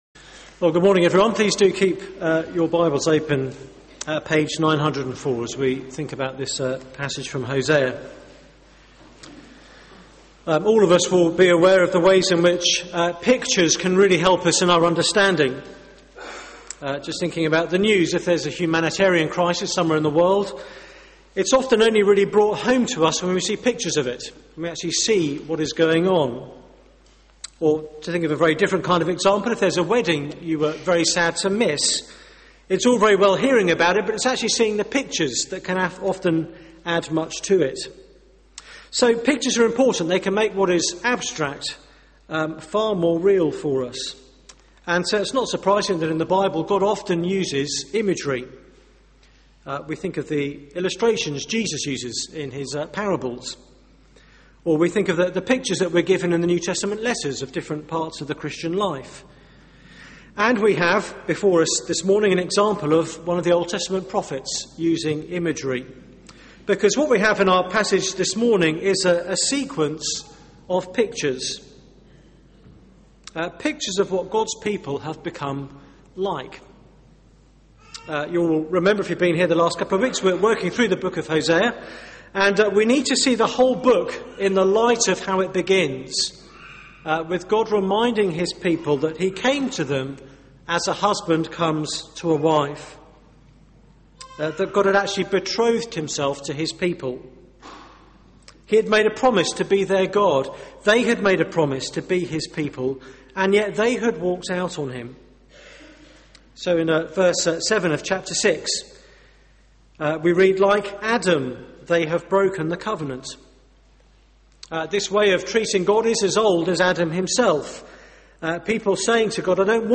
Media for 9:15am Service on Sun 04th Dec 2011 09:15 Speaker
Series: Hosea: A love story Theme: The tragedy of worldliness Sermon